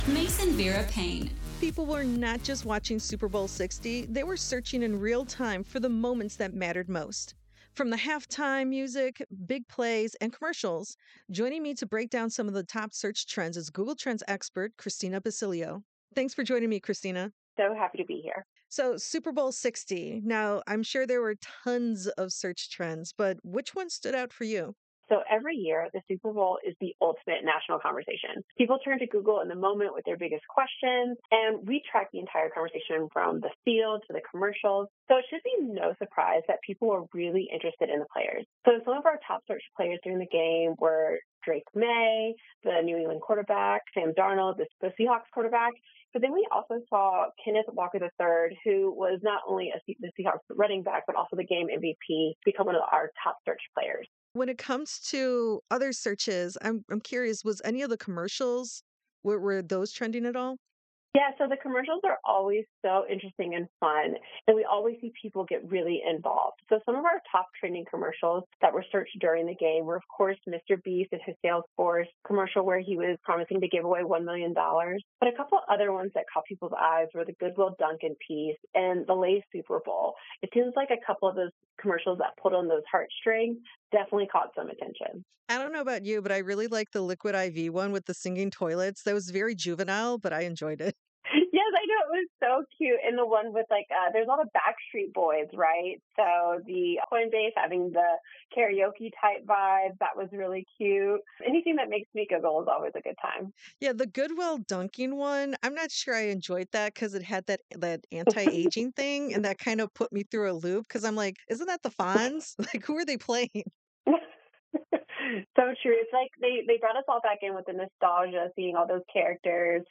Super Bowl 60 Trends Transcript